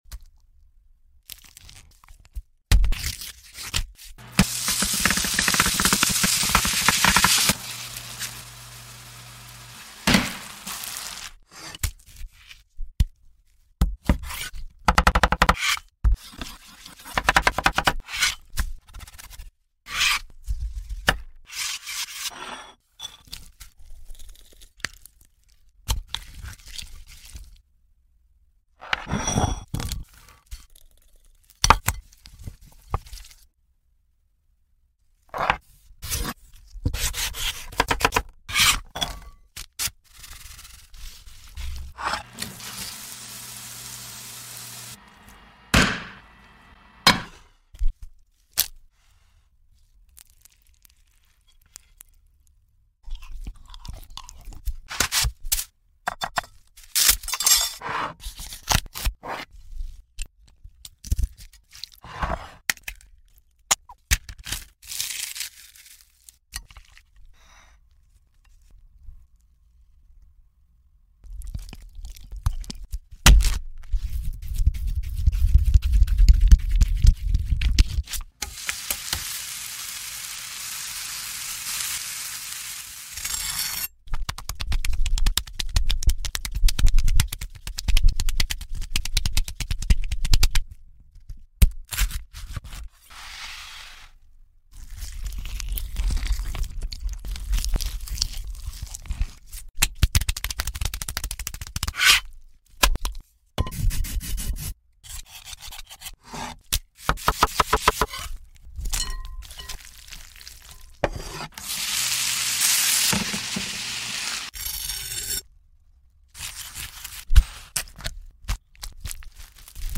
Best Of Zach Choi Foods Sound Effects Free Download